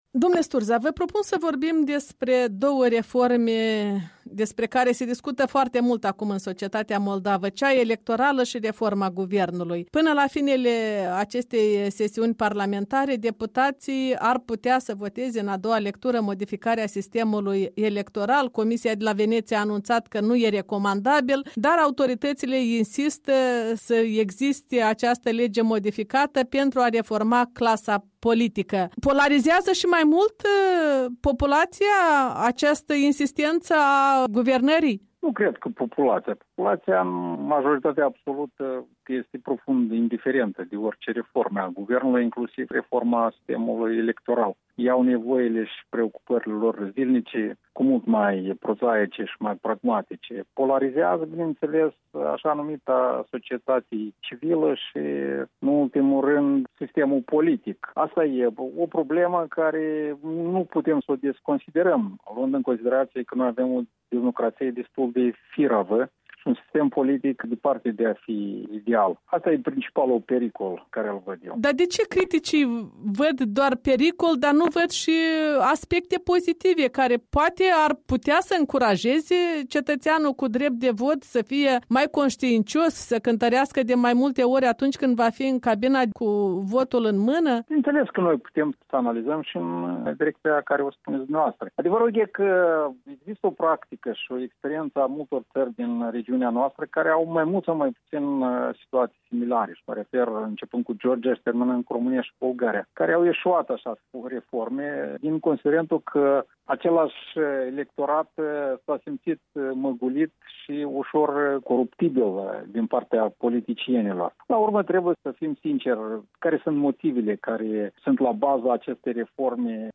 Interviu cu Ion Sturza